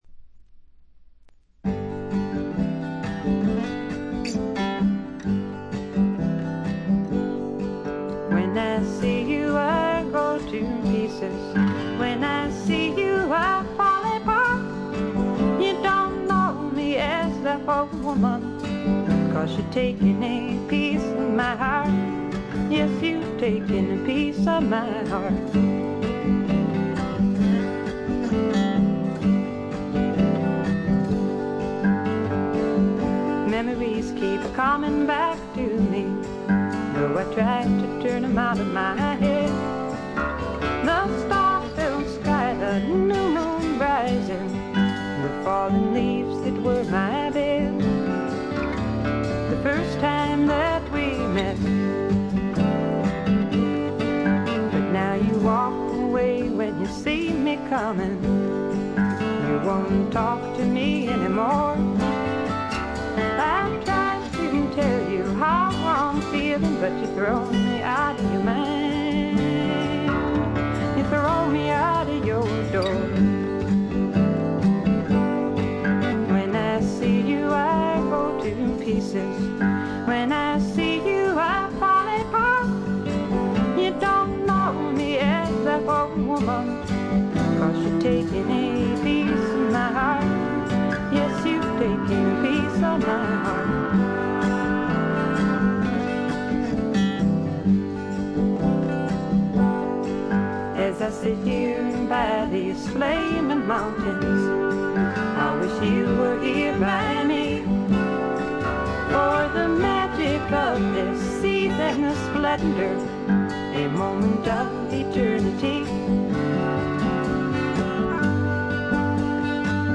女性シンガー・ソングライター、フィメール・フォーク好きには必聴／必携かと思います。
試聴曲は現品からの取り込み音源です。
Recorded at Bearsville Sound Studios, Woodstock, N.Y.
Vocals, Acoustic Guitar